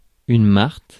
Ääntäminen
Synonyymit martre Ääntäminen Tuntematon aksentti: IPA: /maʁt/ Haettu sana löytyi näillä lähdekielillä: ranska Käännös Ääninäyte Substantiivit 1. marten US Suku: m .